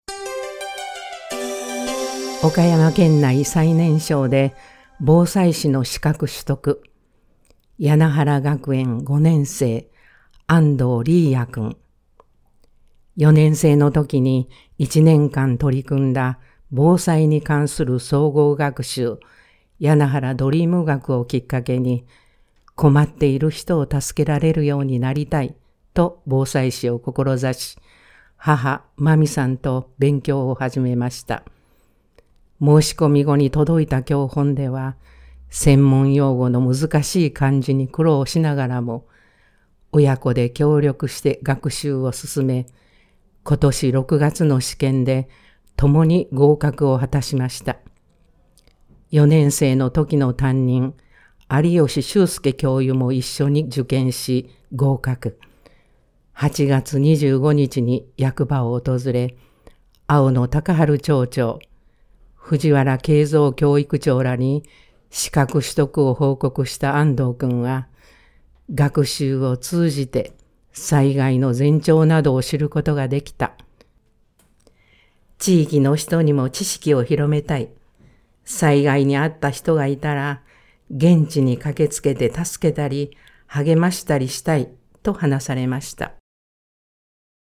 声の広報（広報紙の一部を読み上げています）